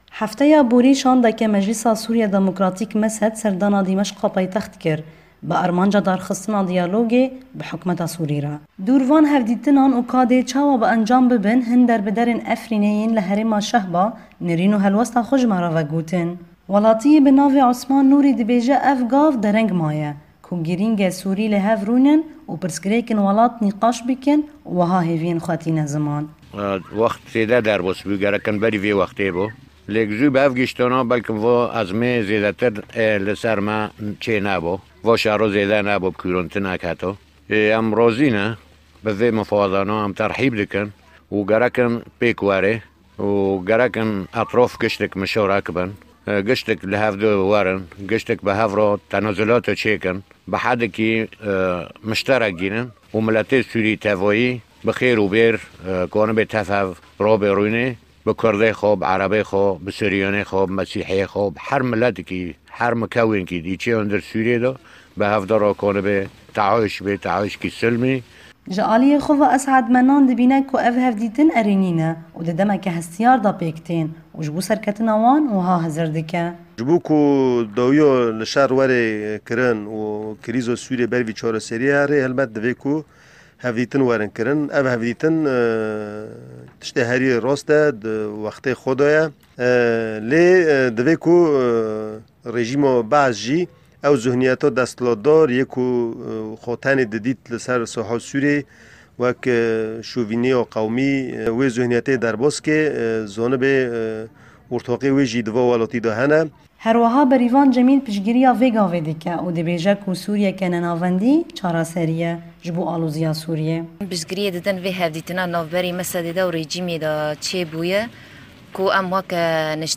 Çend kes ji koçberên Efrînê nêrînên xwe li ser danûstendinên MSD"ê bi Dîmeşqê re vedibêjin